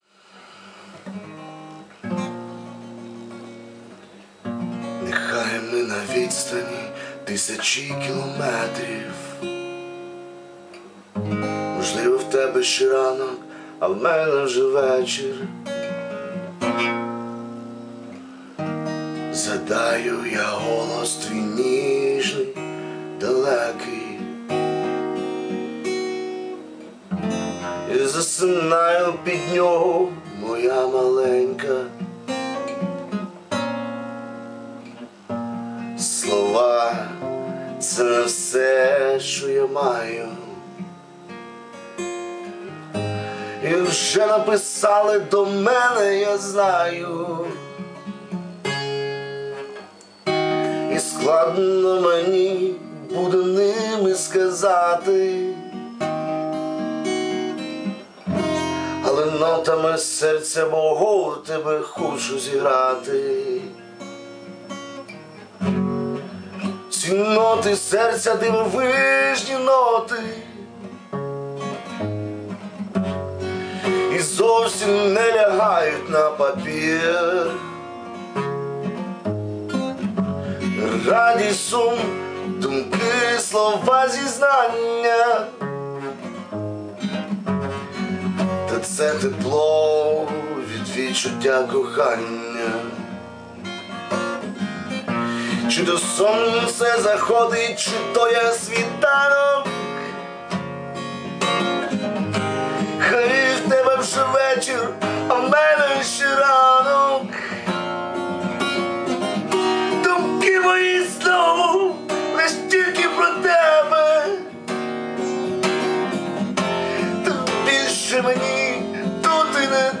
ТИП: Пісня
СТИЛЬОВІ ЖАНРИ: Ліричний
То Ви просвистіли в кінці, чи мені здалося? smile